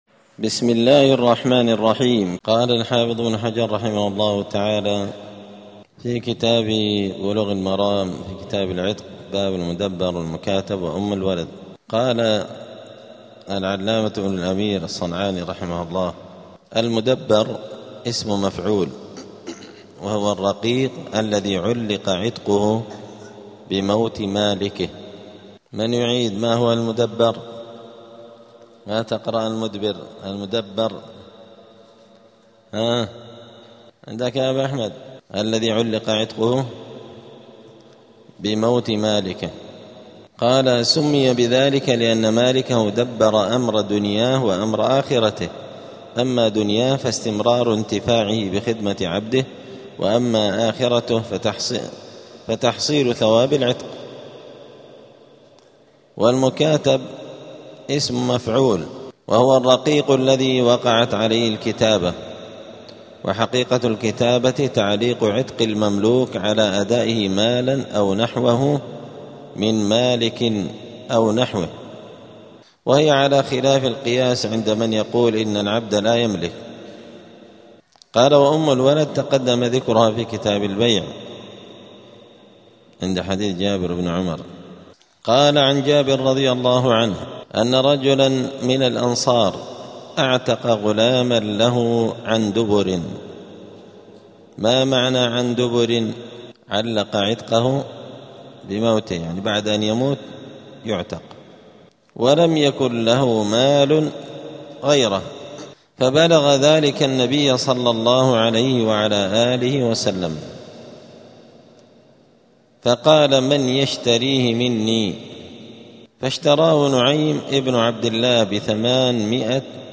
*الدرس الخامس (5) {ﺑﺎﺏ اﻟﻤﺪﺑﺮ ﻭاﻟﻤﻜﺎﺗﺐ ﻭﺃﻡ اﻟﻮﻟﺪ}*
دار الحديث السلفية بمسجد الفرقان قشن المهرة اليمن